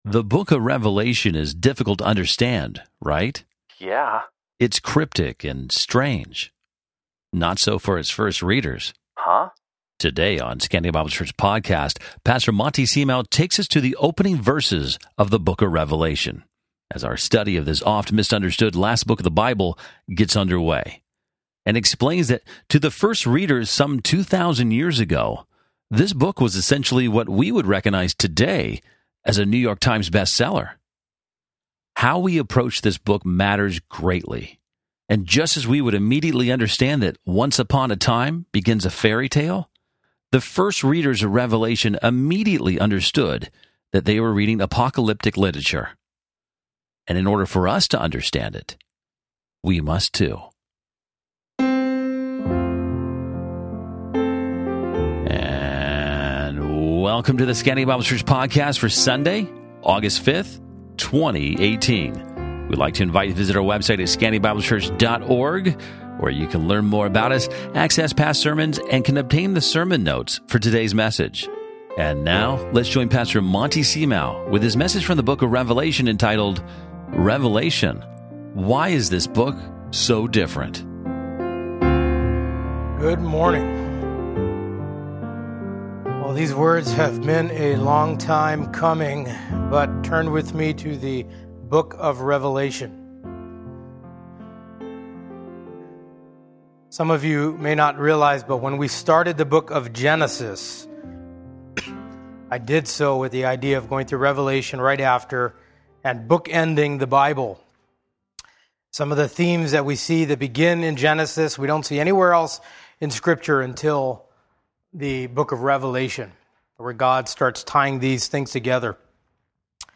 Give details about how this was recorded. Gratefully, the audio podcast recorded the sermon without error.